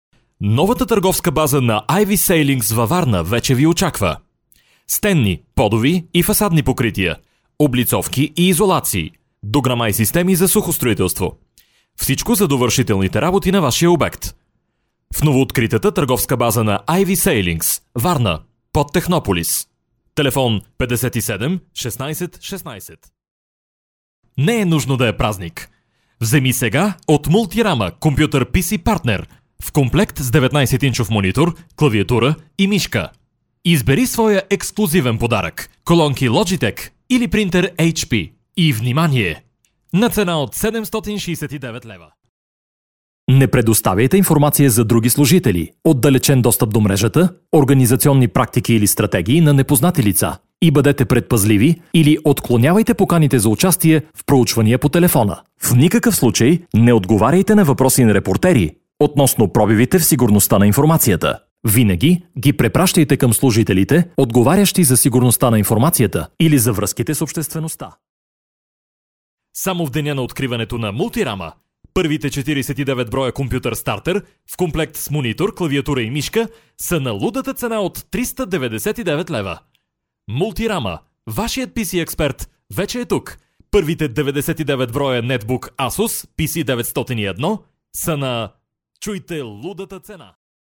Männlich